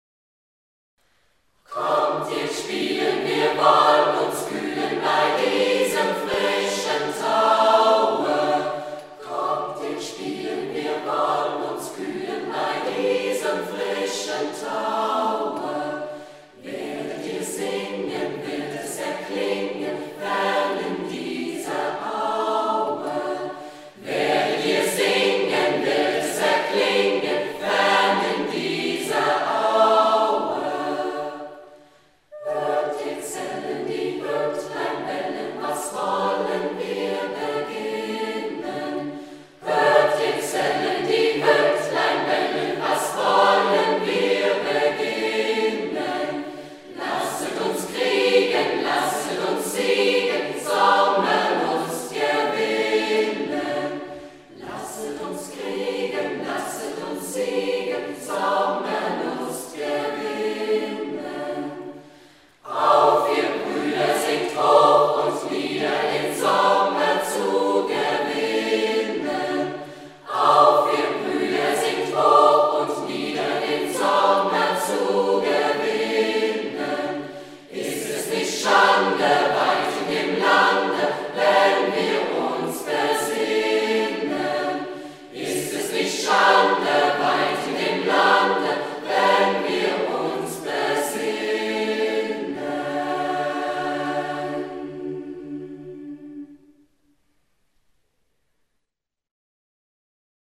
Saxony / Sachsen [about Saxony]
KommtihrGspielenchildrenchoir.mp3